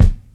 Kick_Brazilian.wav